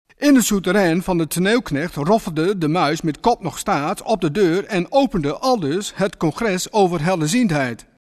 Deze soundtracks zijn opgenomen in Smusic Studio, Amsterdam als onderdeel van experimentele poëzie/voordracht.